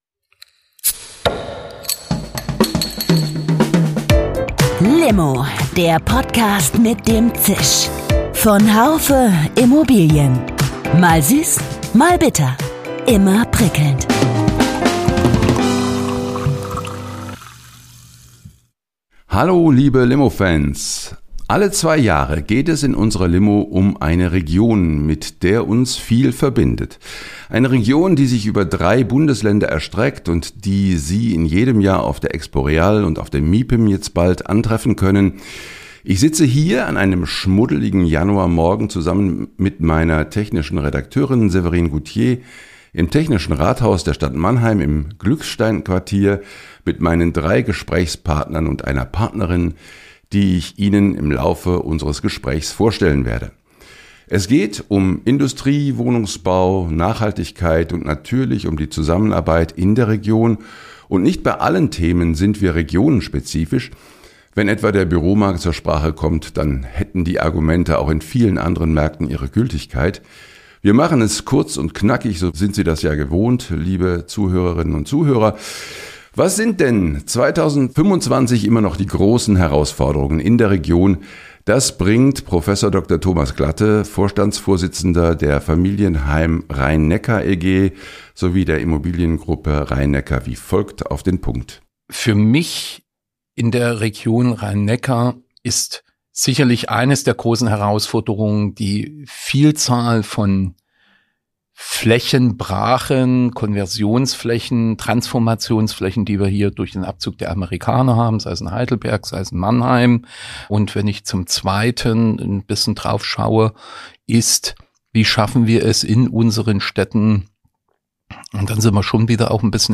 Die wichtigsten Statements eines intensiven Gesprächs über den Büro- und den Wohnungsmarkt in knackiger Form.